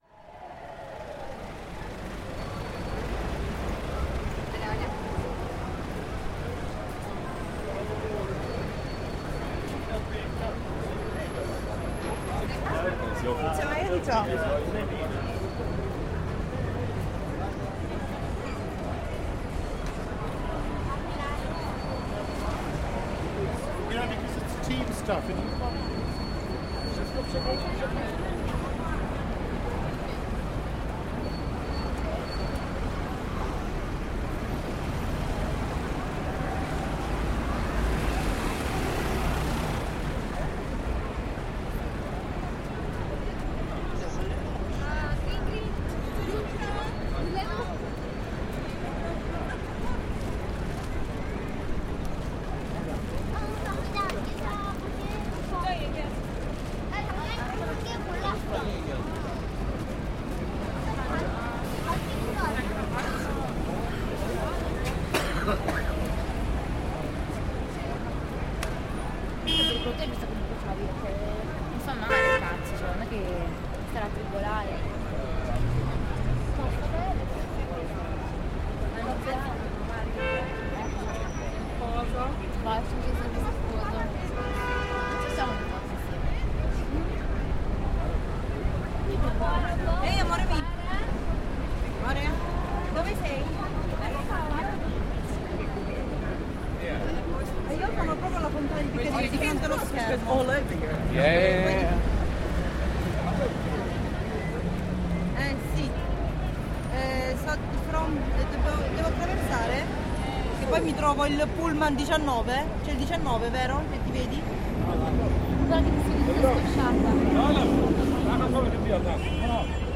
Piccadilly Circus - car horns and tourists from around the world
Field recording from the London Underground by Cities and Memory.